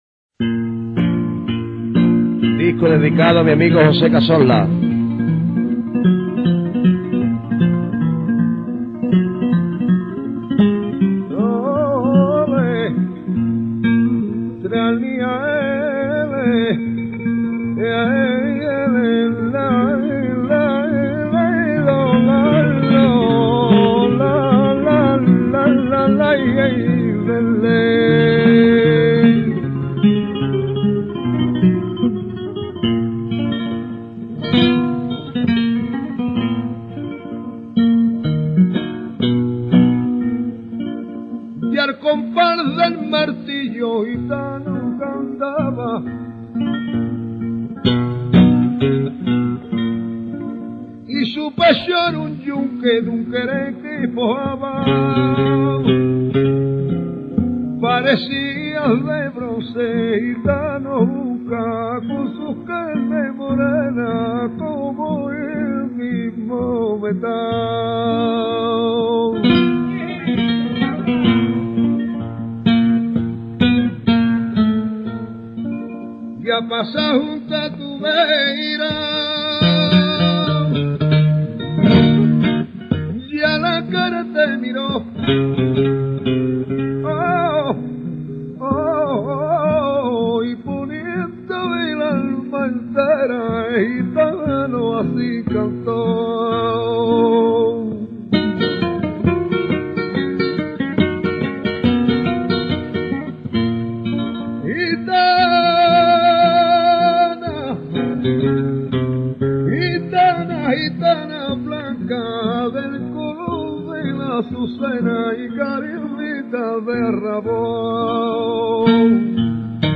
El cante y el toque que lo acompa�an, resulta algo mon�tono por su ritmo sin altos ni bajos, continuo y uniforme. Se toca por en medio y por arriba �nicamente, aunque lo m�s usual es hacerlo por en medio.
zambra.mp3